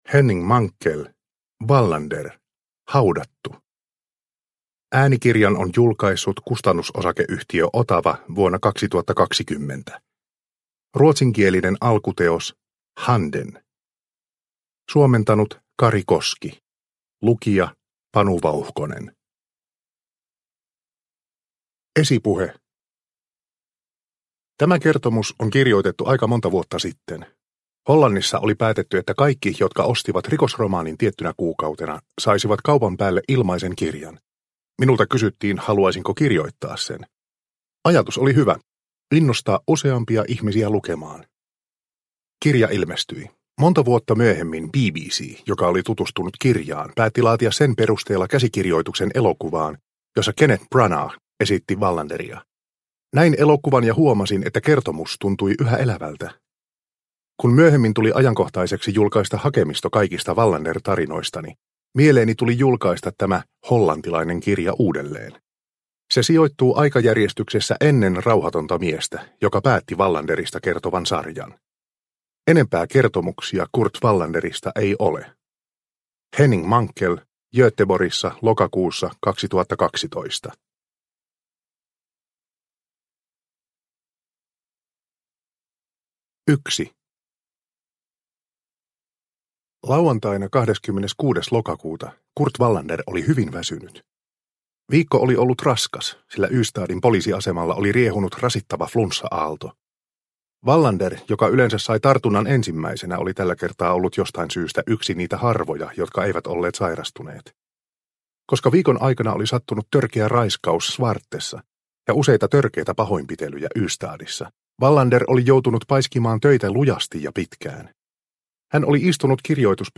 Haudattu – Ljudbok – Laddas ner